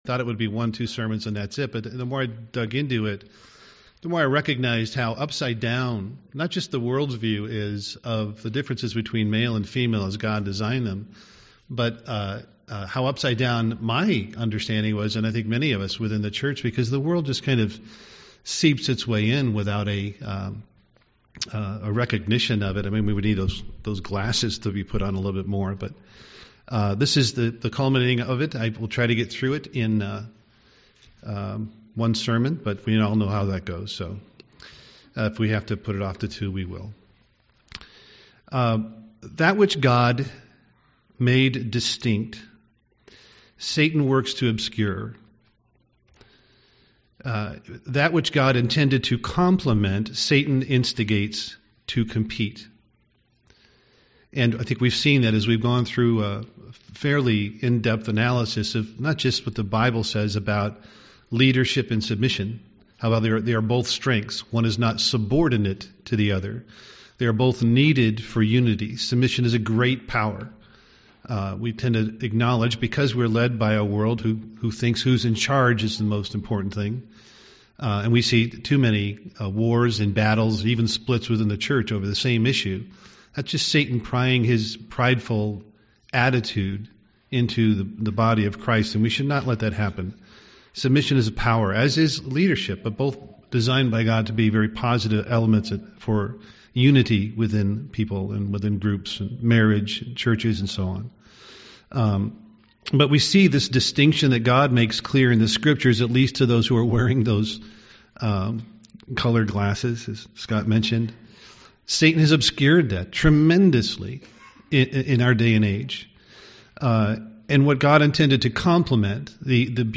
UCG Sermon man woman gender identity gender roles gender Studying the bible?